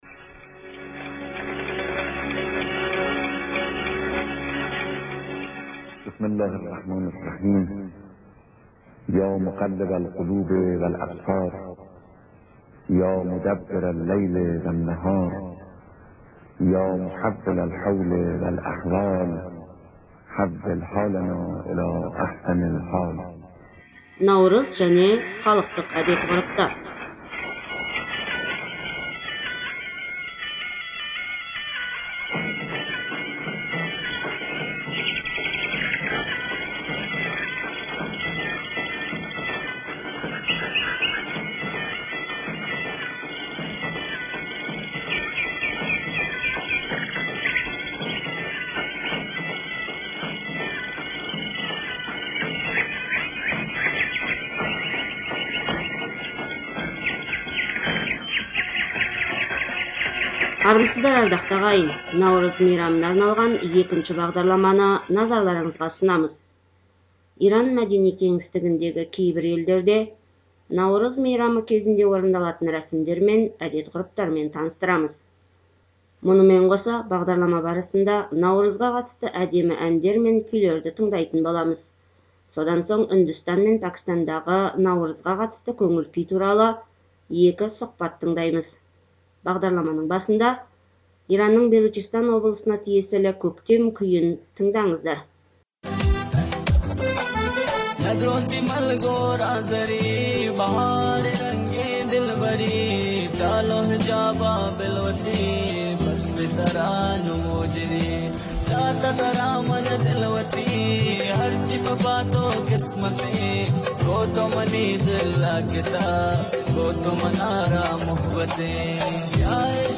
Мұнымен қоса, бағдарлама барысында Наурызға қатысты әдемі әндер мен күйлерді тыңдайтын боламыз. Содан соң Үндістан мен Пәкістадағы Наурызға қатысты көңіл күй туралы екі сұхбат тыңдаймыз. Бағдарламаның басында Иранның Белучистан облысына тиесілі көктем күйін тыңдаңыздар.